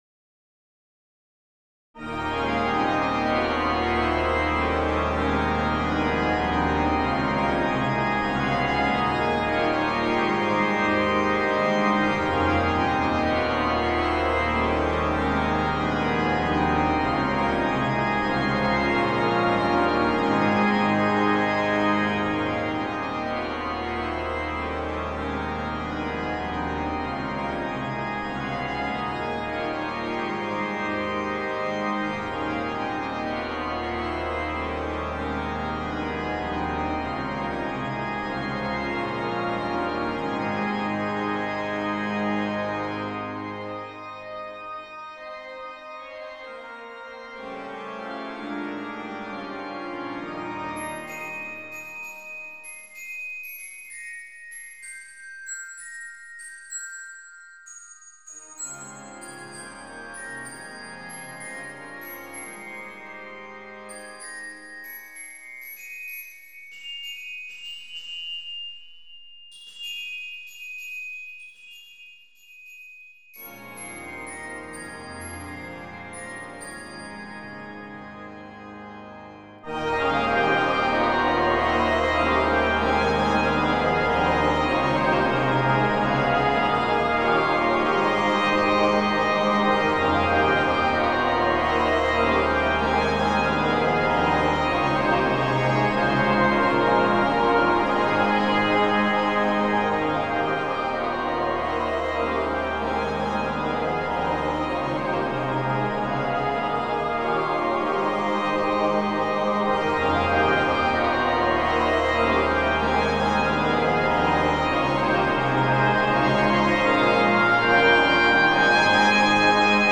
for Glockenspiel, Choir & Organ